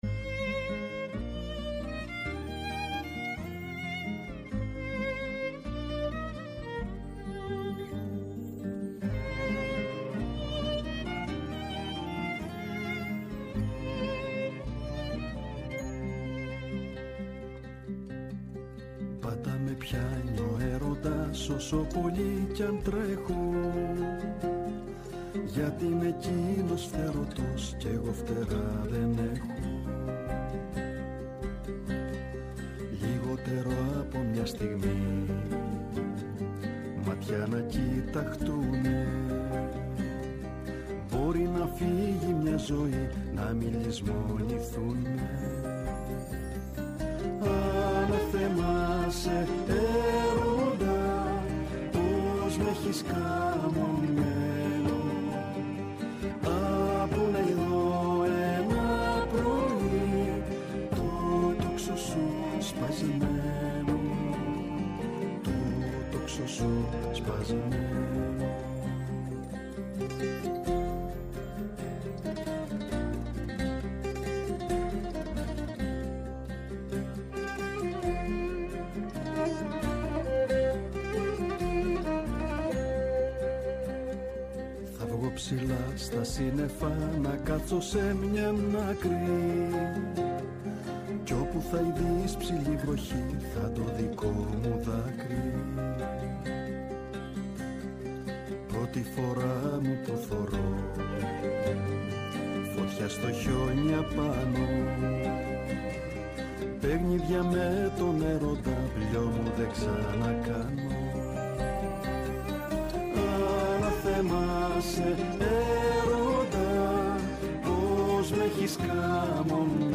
τραγούδησε ζωντανά στο στούντιο αυτό το τραγούδι
ΔΕΥΤΕΡΟ ΠΡΟΓΡΑΜΜΑ Παντος Καιρου Live στο Studio Αφιερώματα Μουσική Συνεντεύξεις